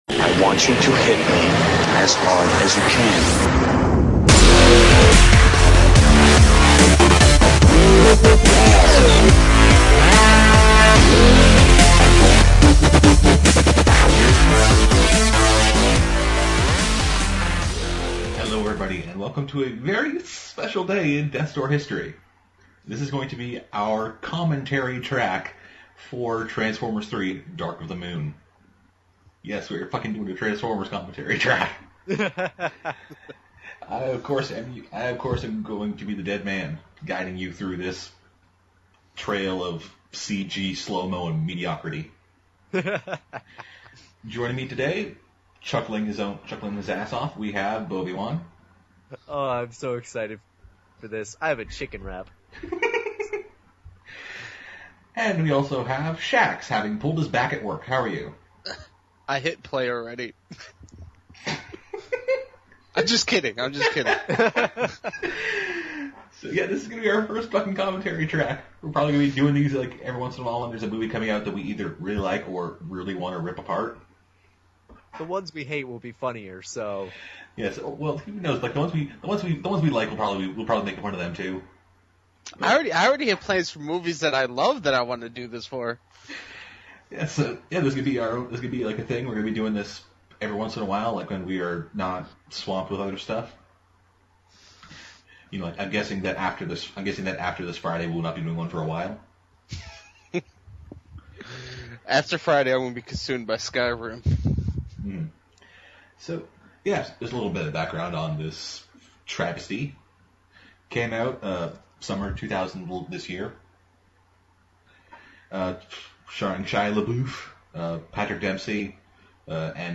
Transformers 3 Commentary